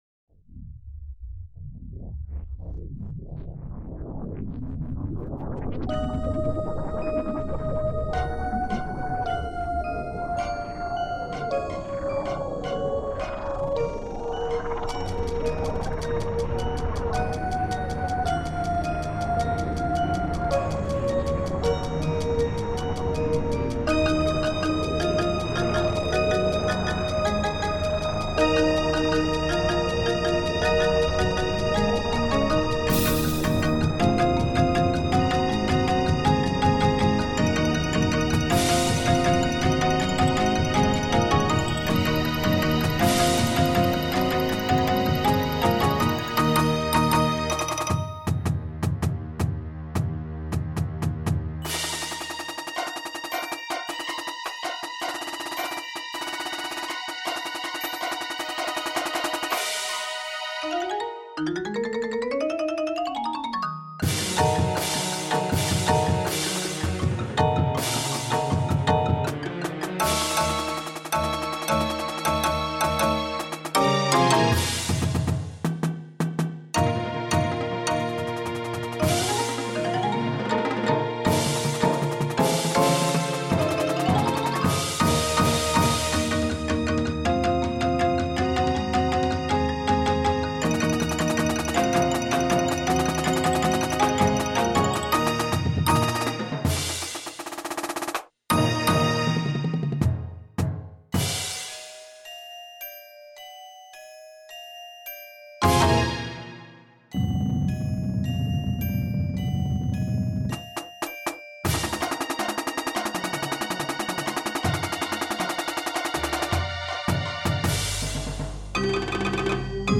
• Bells/Glockenspiel
• Xylophone
• 2 or 3 - Vibraphones
• 2 or 4 - Marimbas
• 2 Synthesizers
• Timpani
• Snare Drum
• Tenor Drums (quads with 1 or 2 spocks)
• 5 Bass Drums
• Marching Hand Cymbals
• Assorted Big Drums